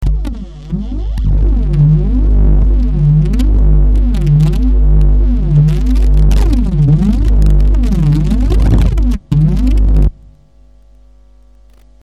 描述：Org. Guitar. Flute and Imagination. All played by myself
标签： 100 bpm Weird Loops Groove Loops 2.02 MB wav Key : Unknown
声道立体声